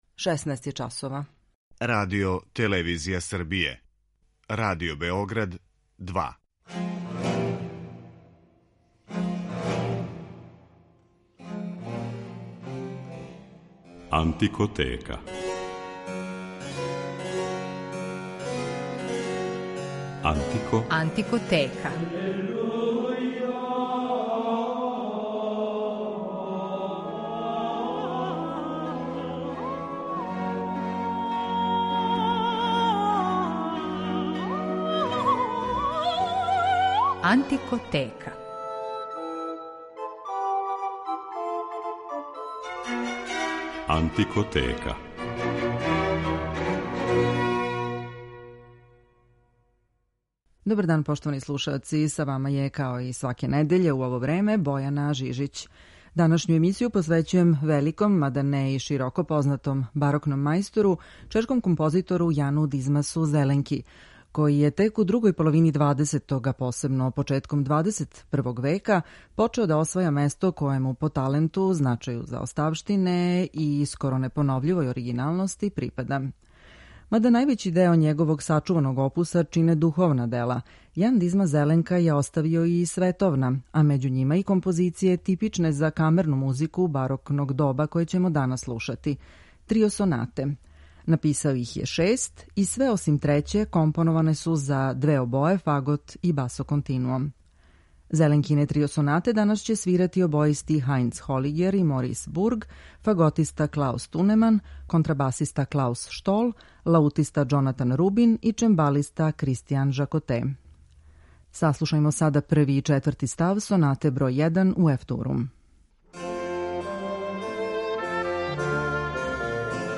Међу њима су и остварења типична за камерну музику барокног доба која ћемо данас представити, трио сонате.